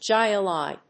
/hάɪlɑɪ(米国英語)/